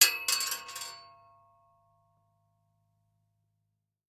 household
Coin Dime Dropping on Metal Surface